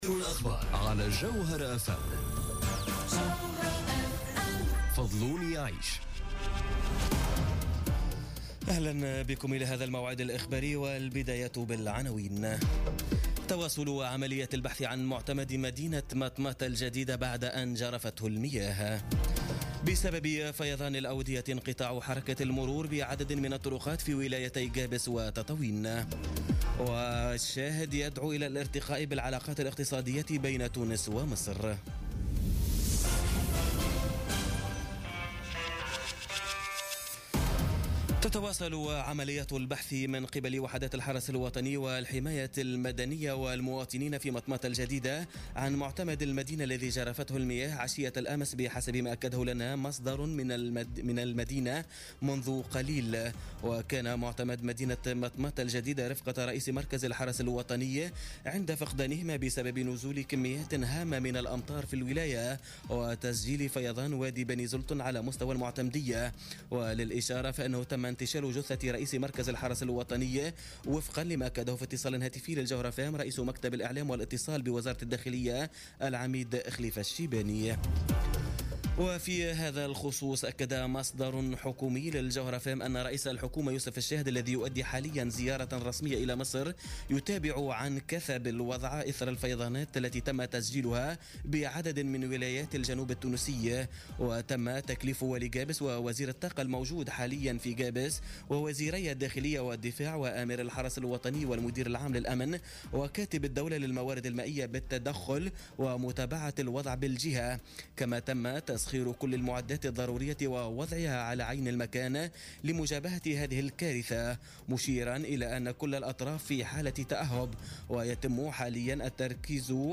نشرة أخبار منتصف الليل ليوم الاحد 12 نوفمبر 2017